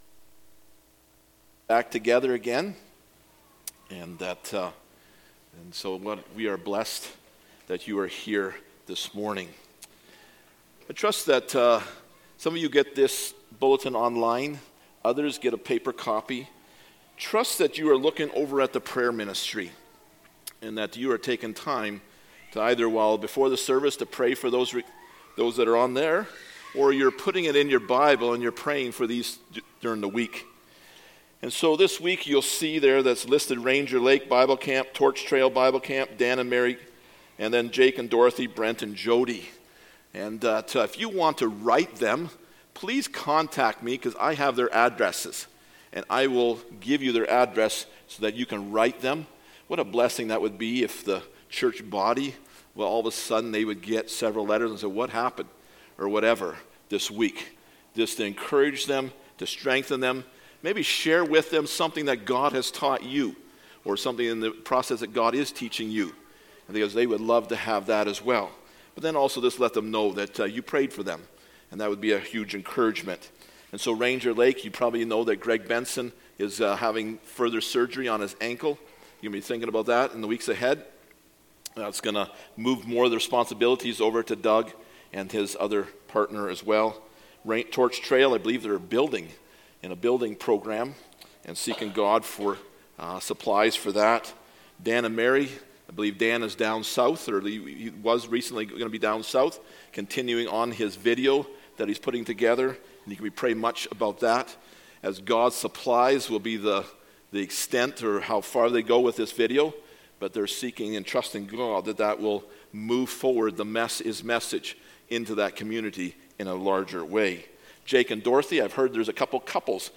1 Corinthians 9:16-23 Service Type: Sunday Morning Topics: Stewardship